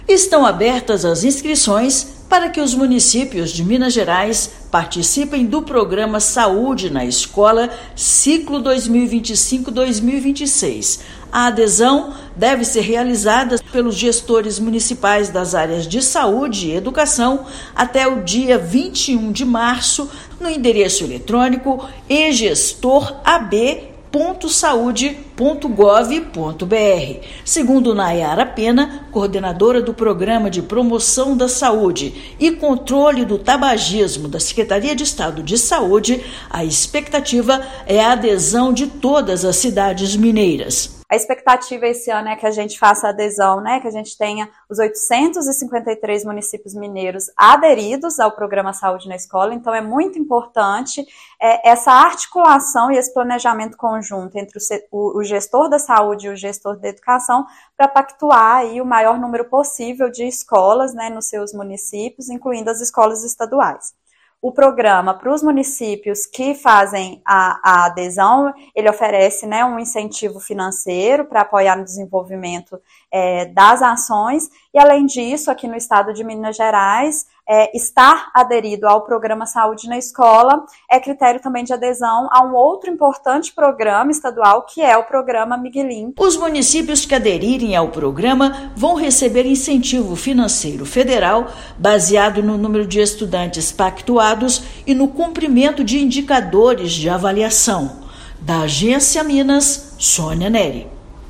[RÁDIO] Municípios mineiros podem aderir ao ciclo 2025/2026 do Programa Saúde na Escola
Programa incentiva ações de promoção da saúde e prevenção de doenças para jovens em escolas públicas, com inscrições abertas até 21/3. Ouça matéria de rádio.